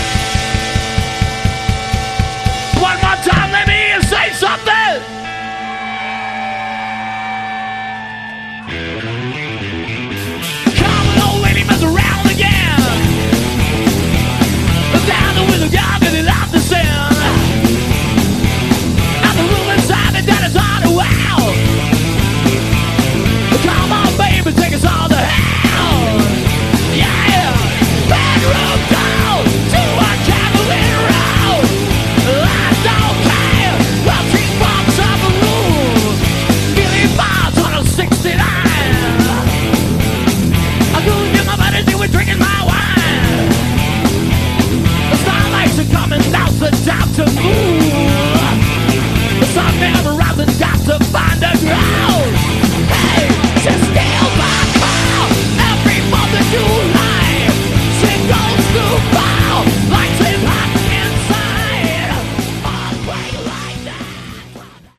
Category: Glam
guitar
vocals
bass
drums